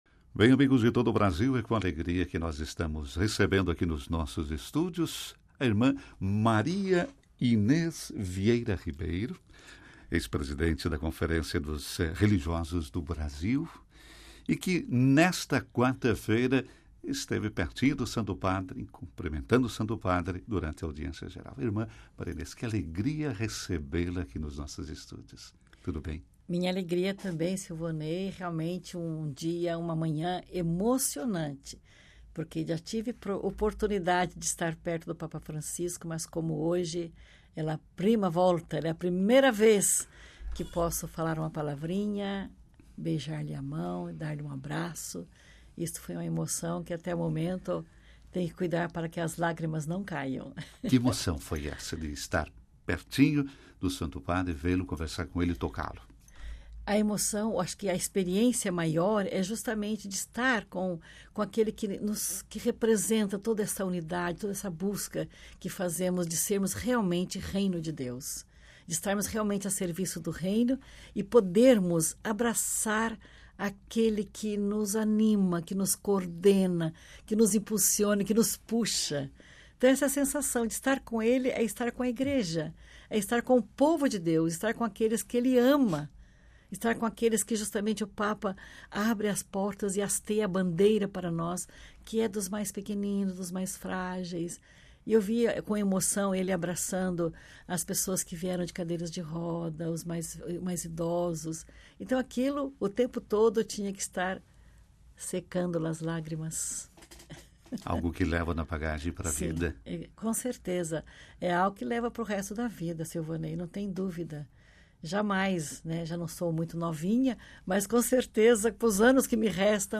Eis a íntegra da conversa.